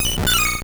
Cri de Marill dans Pokémon Or et Argent.